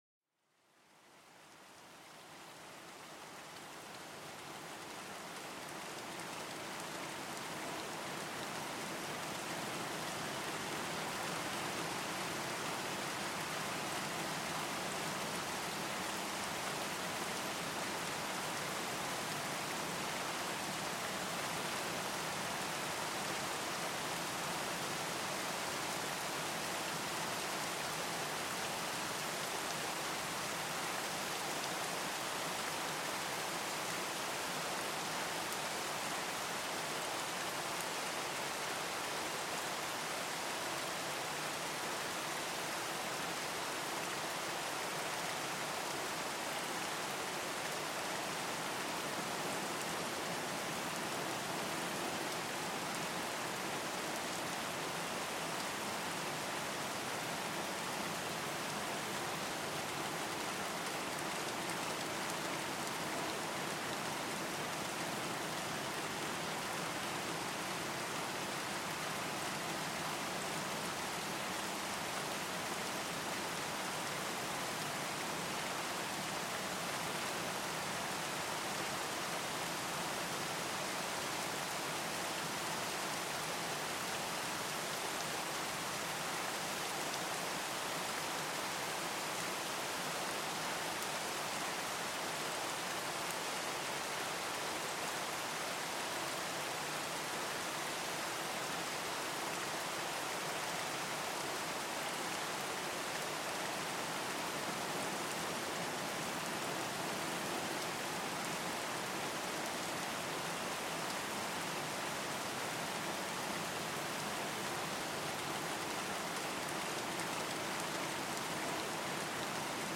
Pluie forte : une évasion sonore pour apaiser l'esprit